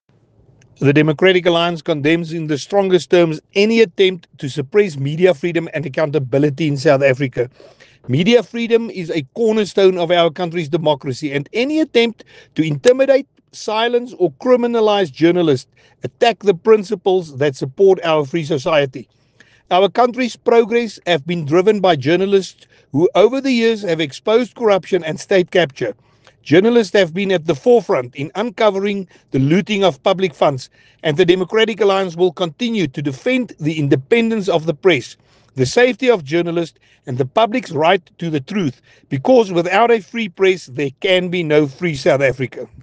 Afrikaans soundbite by Willie Aucamp MP.